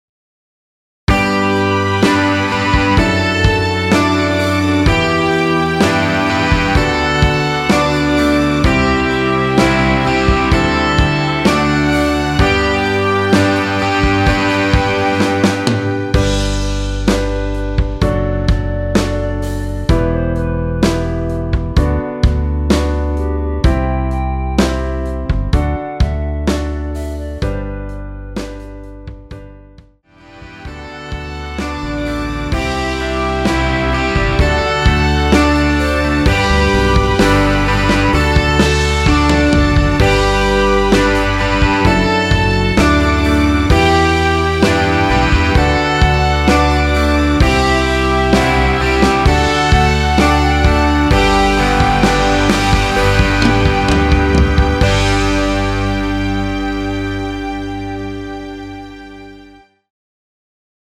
원키 멜로디 포함된 MR입니다.
멜로디 MR이라고 합니다.
앞부분30초, 뒷부분30초씩 편집해서 올려 드리고 있습니다.
중간에 음이 끈어지고 다시 나오는 이유는